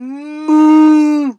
pgs/Assets/Audio/Animal_Impersonations/cow_2_moo_03.wav at master
cow_2_moo_03.wav